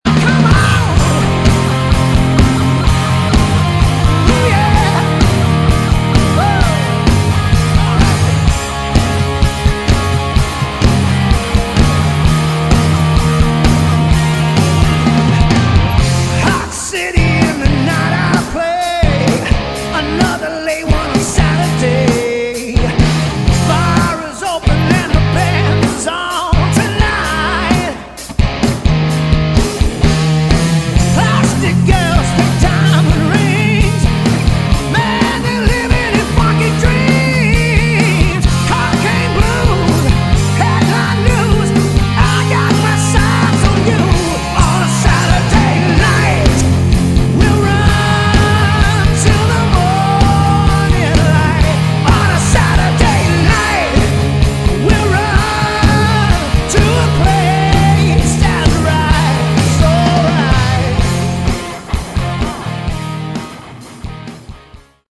Category: Hard Rock
Lead Vocals, Acoustic Guitar
Backing Vocals
Guitar
Bass
Drums